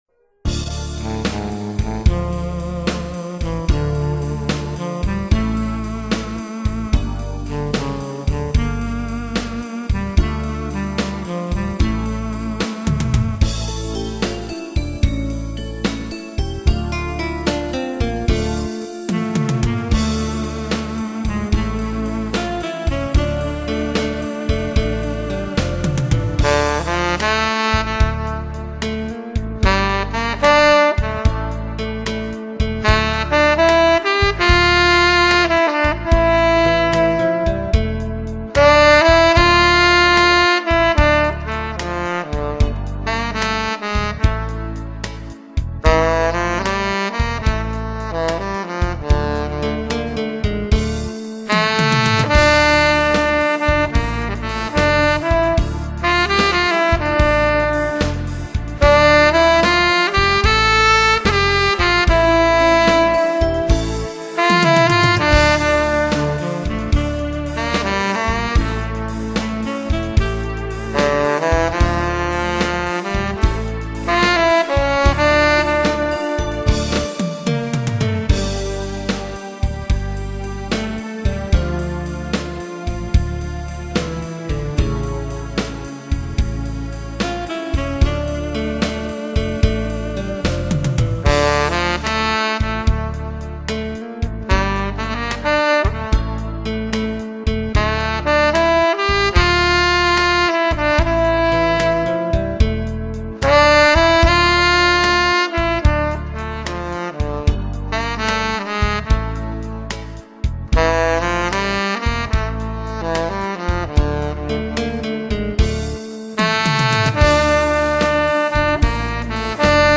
아마추어의 색소폰 연주
이번에는 처음으로 동호회 연습실에서 정식 녹음장비로 녹음했습니다.
느낌은 꼭 초등생 국어책 읽듯이 감정은 하나도 없구...